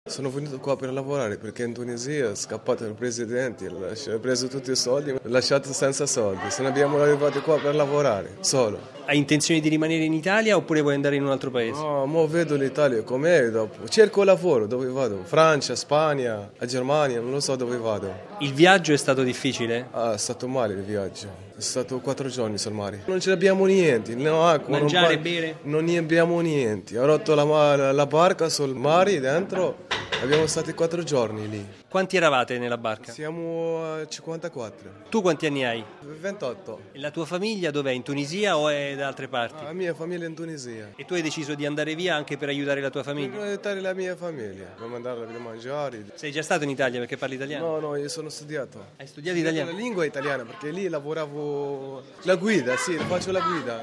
Ma ascoltiamo la testimonianza di un giovane immigrato tunisino, sbarcato a Lampedusa in cerca di una vita migliore.